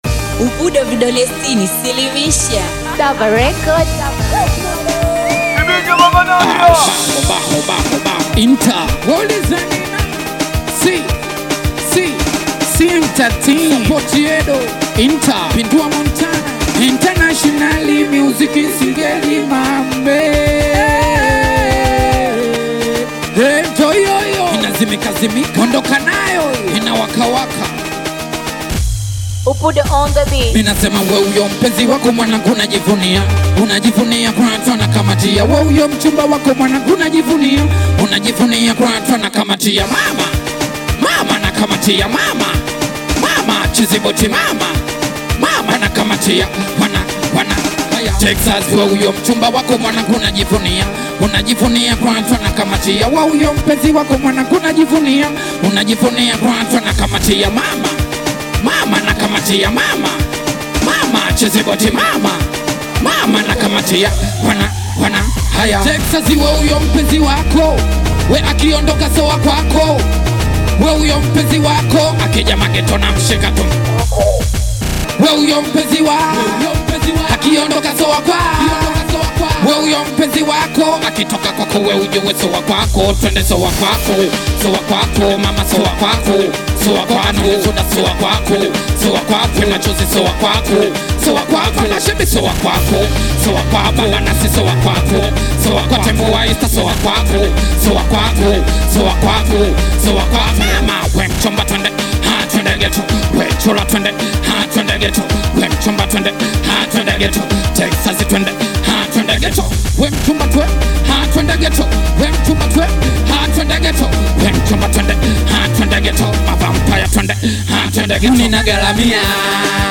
With a seamless blend of electronic beats and soulful vocals